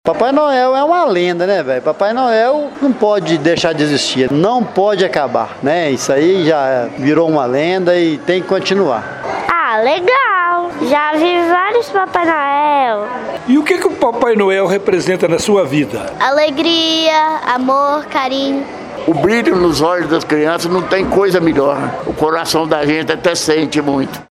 Crianças, jovens e adultos